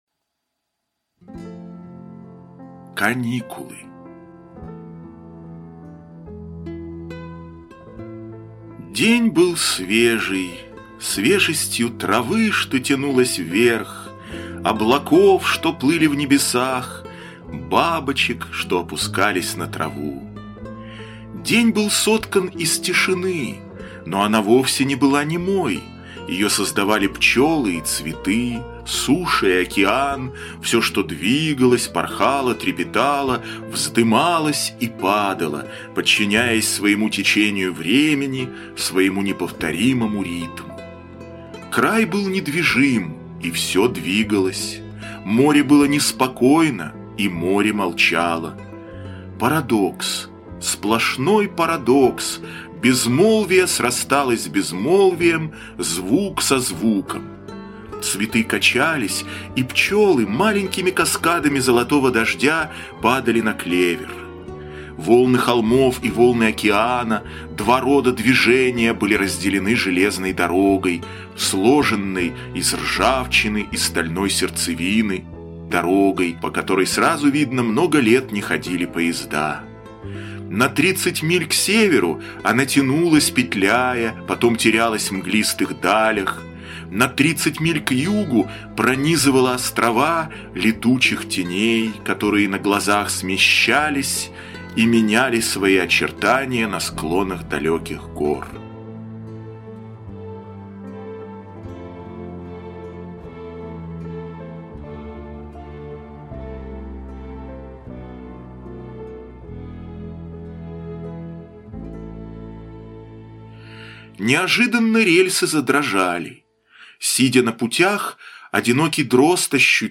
Каникулы - аудио рассказ Брэдбери Р. Рассказ про одну американскую семью, в которой отец помечтал об исчезновении всех людей на Земле.